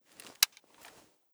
firemode.ogg